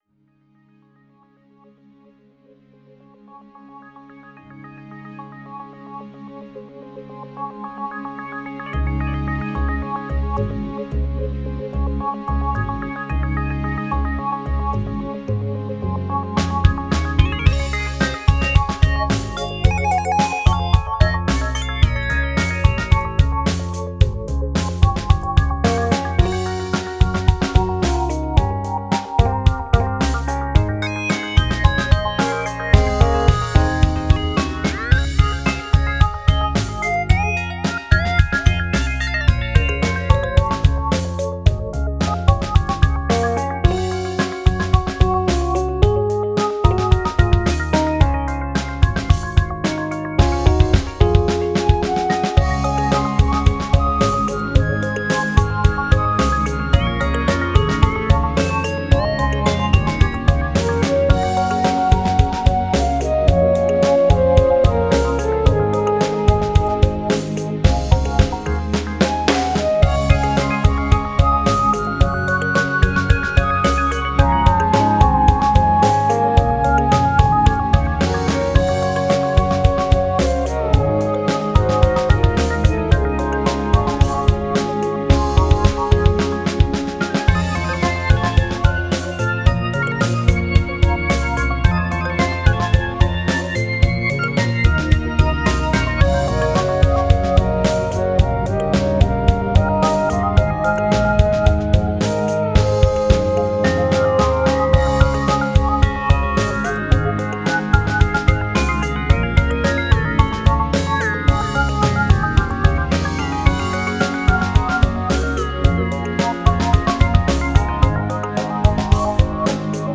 pop/world-styled remix